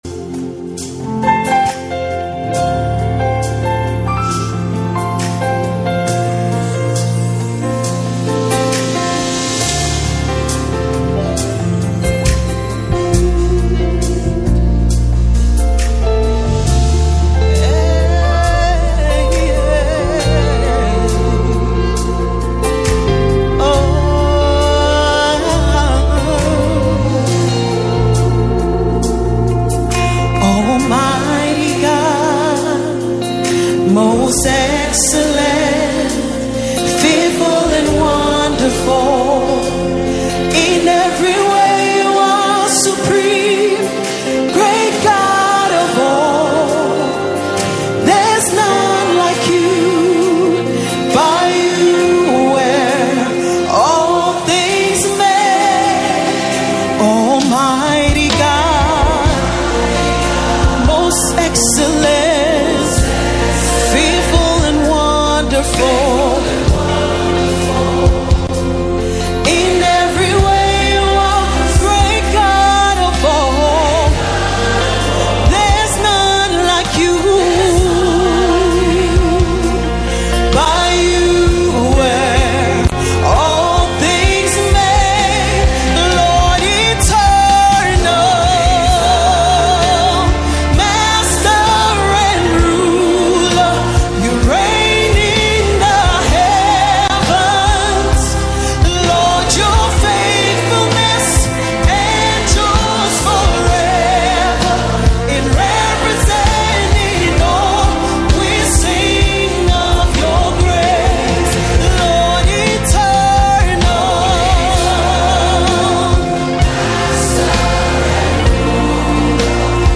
Key – F